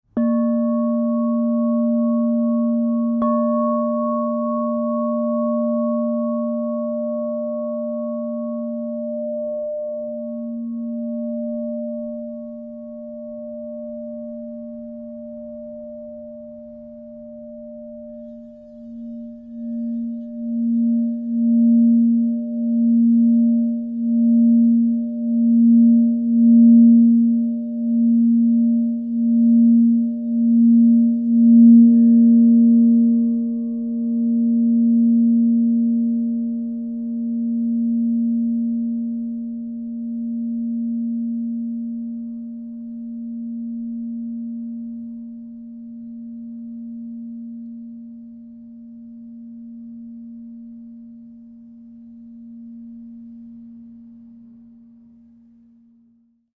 Aqua Aura Gold 10" A# -5 - Divine Sound
Introducing our 10-inch A# True Tone Alchemy Crystal Singing Bowl, a transcendent piece in a stunning translucent teal blue.